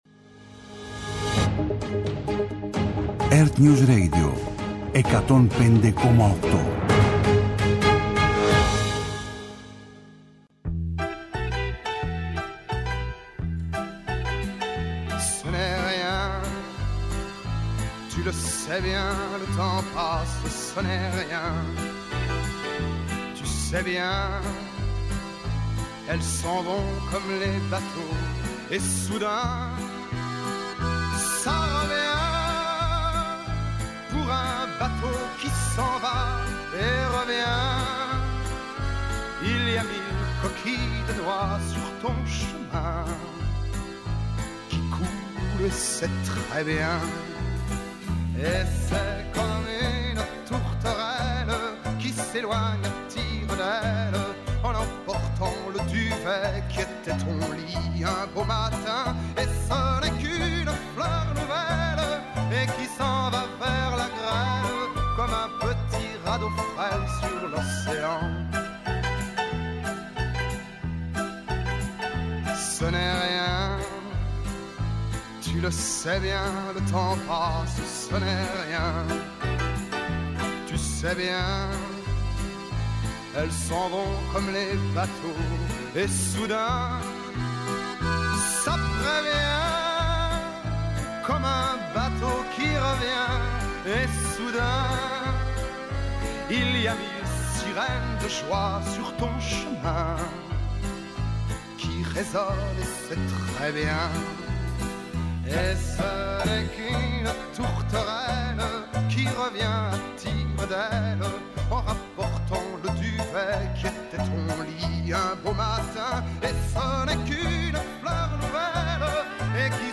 Ενημέρωση με έγκυρες πληροφορίες για όλα τα θέματα που απασχολούν τους πολίτες. Συζήτηση με τους πρωταγωνιστές των γεγονότων.